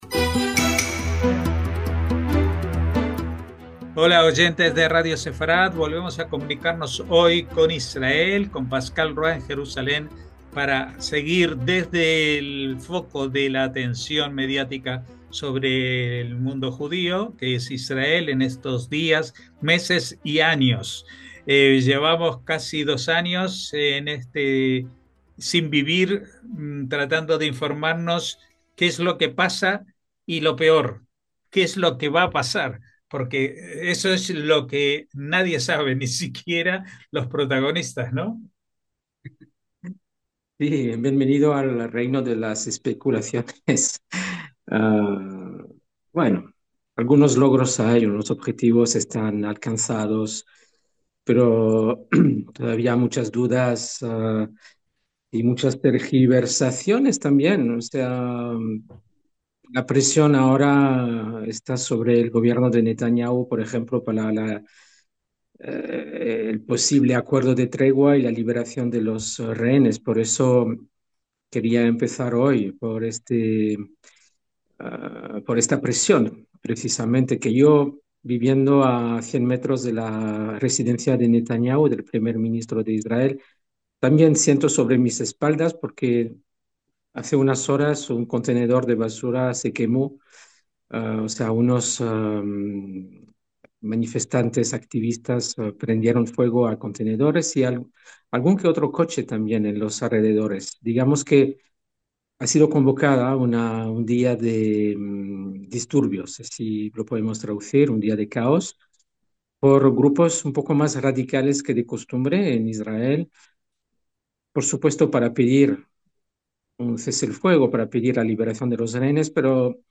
NOTICIAS CON COMENTARIO A DOS
Como tantas veces nos preguntamos en estos casi dos años, ¿qué pasará después? Estas y otras muchas incógnitas forman parte del diálogo de esta entrega.